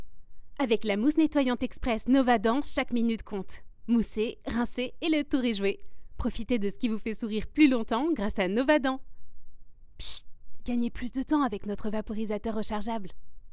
Voix américaine
Une voix qui ne triche pas, et une présence simple, chaleureuse et pleine de vie.
13 - 45 ans - Mezzo-soprano